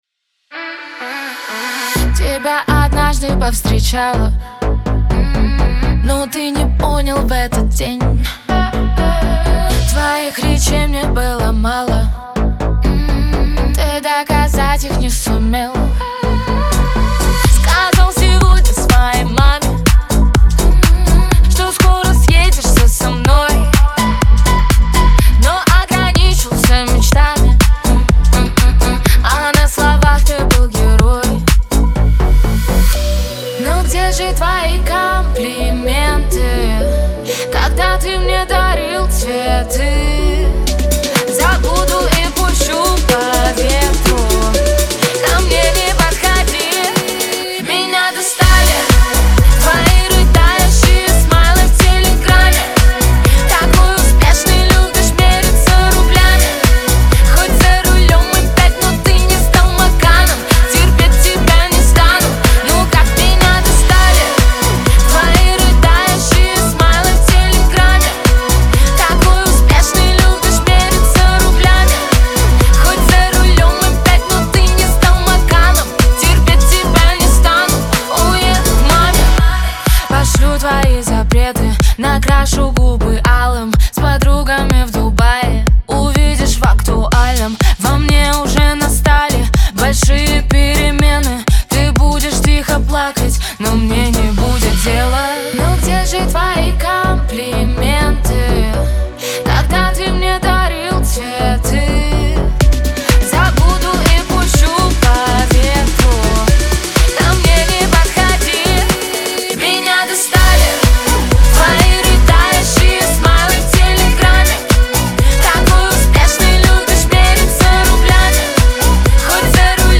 эстрада , pop , диско